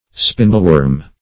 Search Result for " spindleworm" : The Collaborative International Dictionary of English v.0.48: Spindleworm \Spin"dle*worm`\, n. (Zool.)